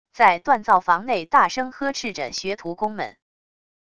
在锻造房内大声呵斥着学徒工们wav音频